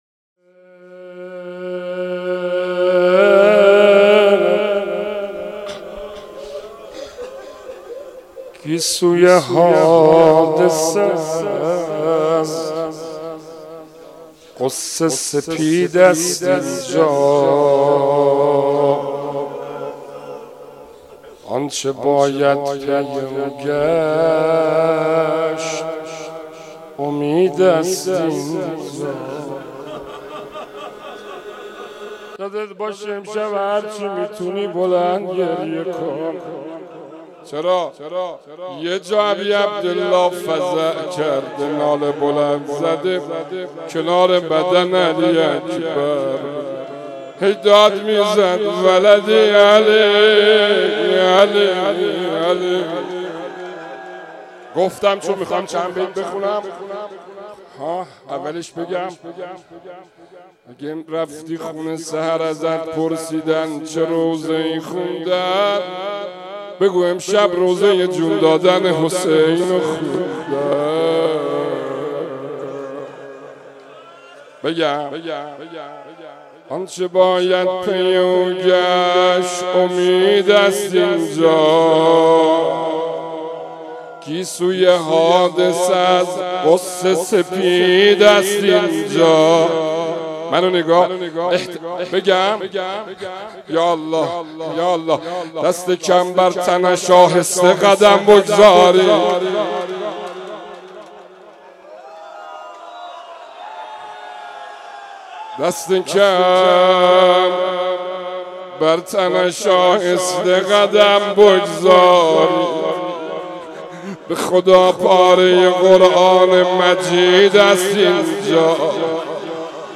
مناسبت : شب نهم رمضان
مداح : محمدرضا طاهری قالب : غزل